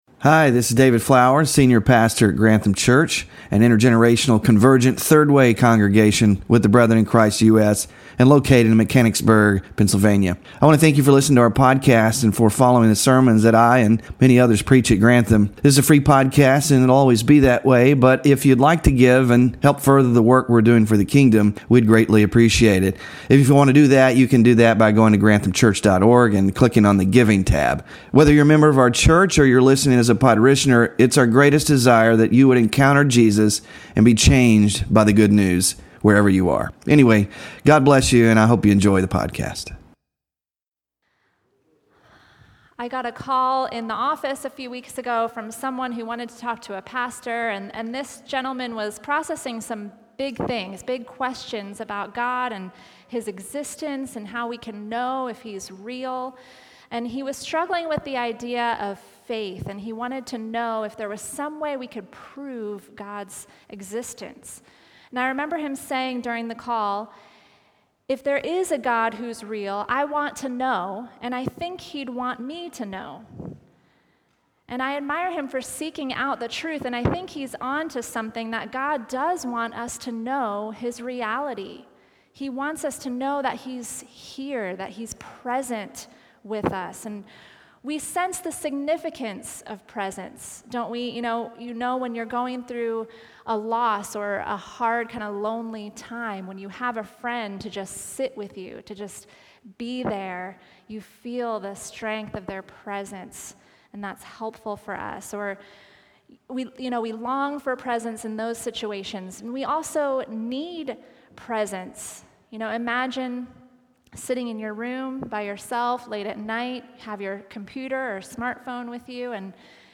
GLORY OF GOD WK 2 SERMON SLIDES SMALL GROUP DISCUSSION QUESTIONS 5-12-24